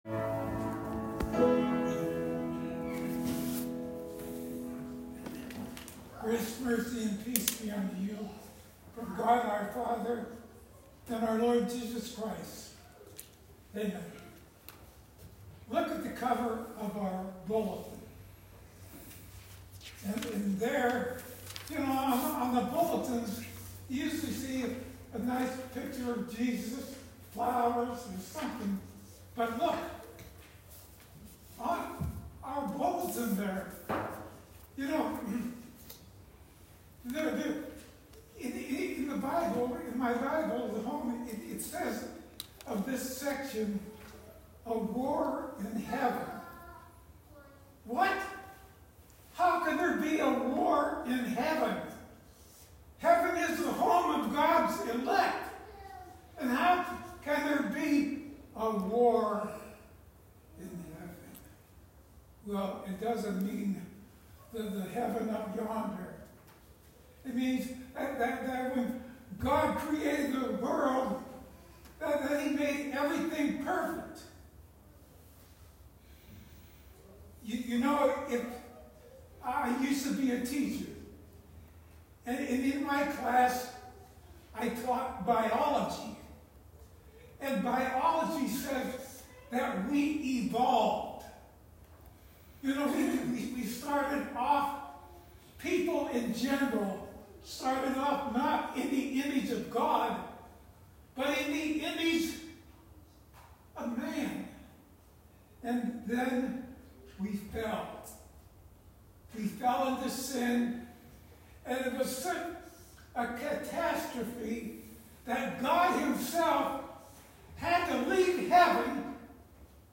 Sermons 2024 | Trinity Evangelical Lutheran Church